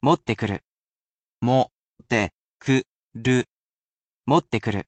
Listening (or Reading) Practice |日本語の聞き取り（若しくは読み取り）練習
We have here with us the portable version of our computer robot friend, QUIZBO™ Mini, who will be here to help read out the audio portions.